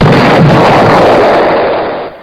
Download Stock Explosion sound effect for free.
Stock Explosion